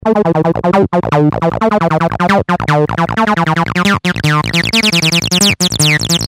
Sound Example 4 - A short loop